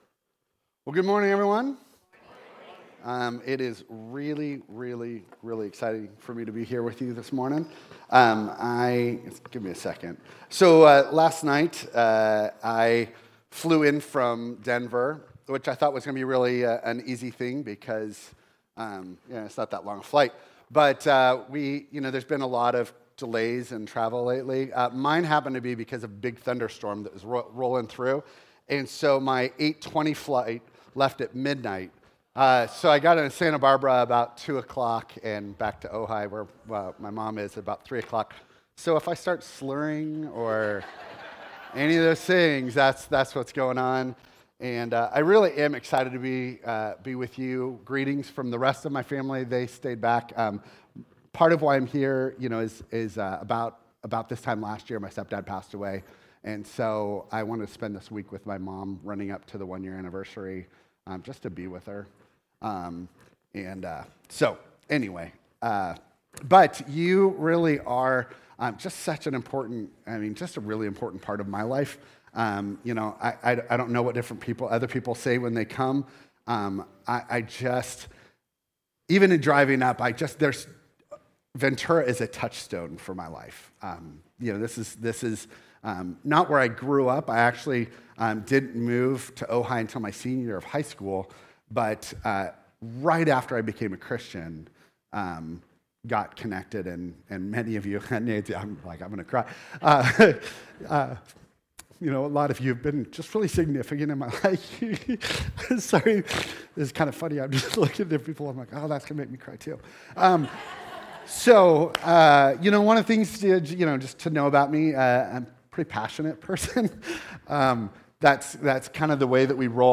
Passage: Colossians 3:1-17 Service Type: Sunday